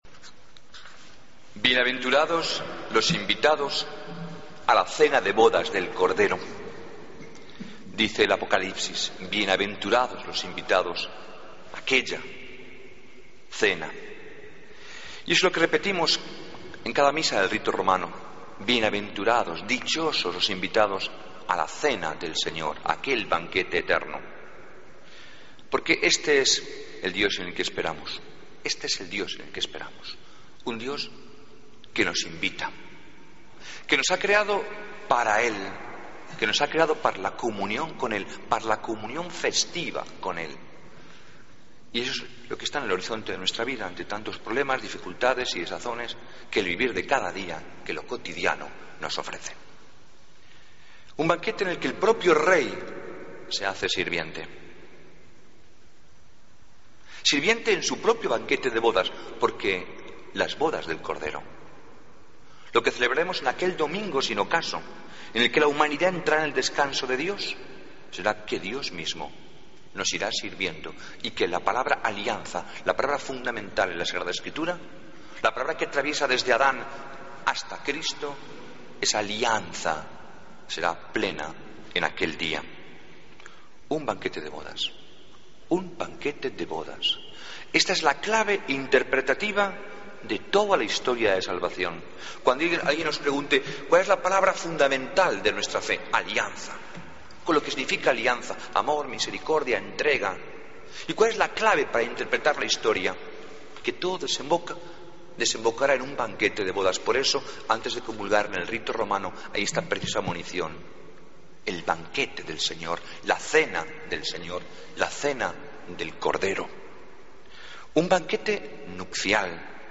Homilía del domingo 12 de octubre de 2015